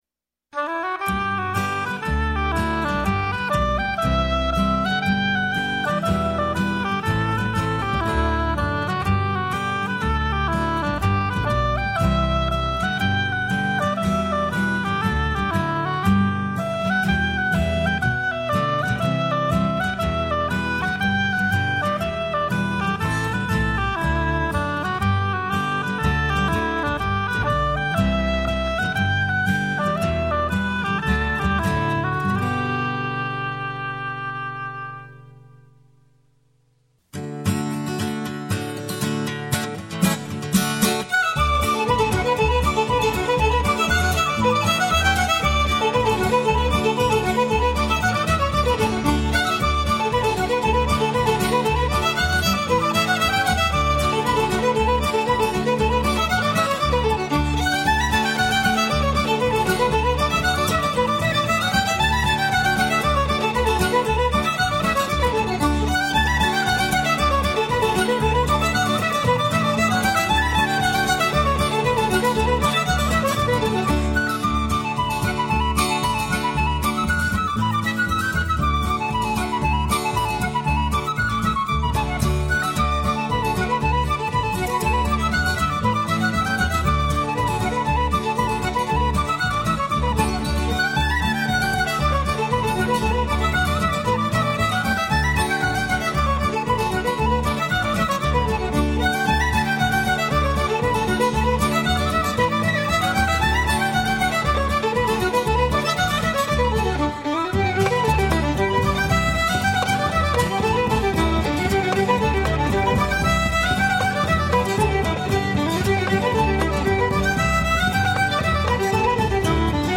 音乐都是加拿大的乡村舞曲，节奏欢快热烈、清爽怡人。乐手使用的都是传统乐器，器乐结像历历在目。